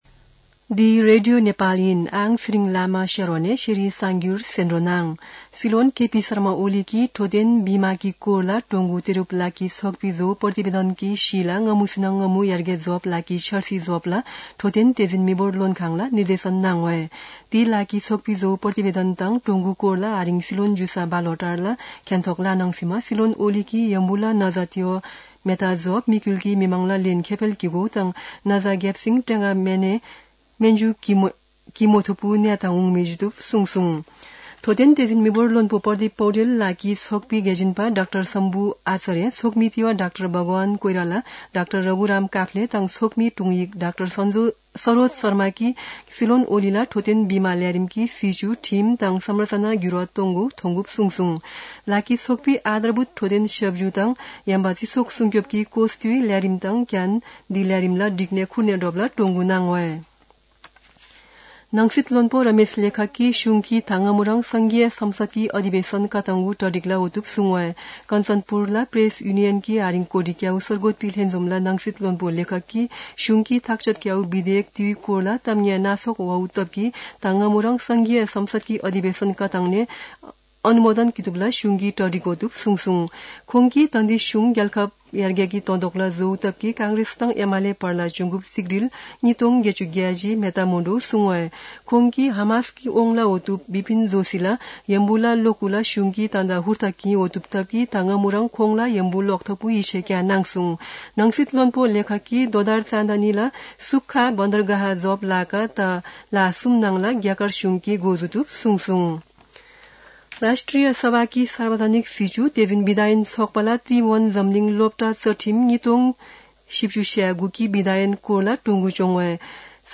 शेर्पा भाषाको समाचार : ७ माघ , २०८१
Sherpa-News-5.mp3